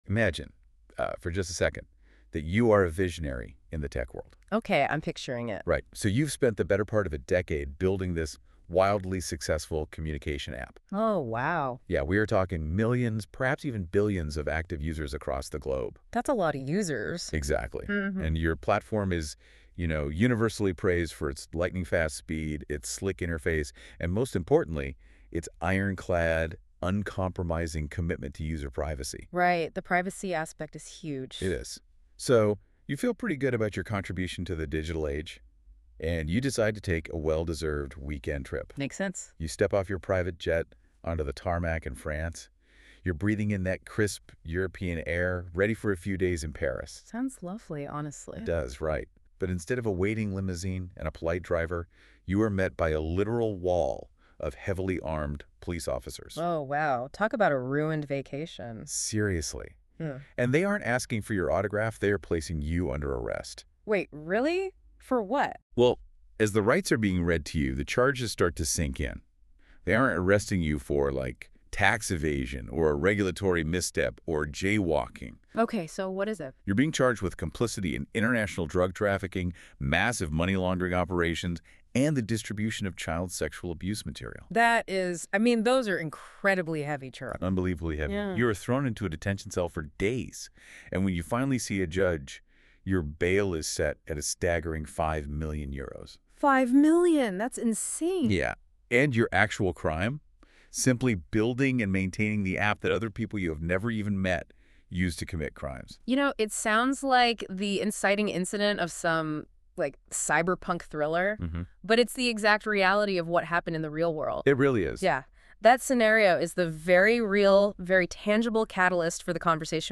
We have also auto-generated a podcast using NotebookLM. It takes a more playful and hyperbolic tone than the article itself, but it captures the main ideas well.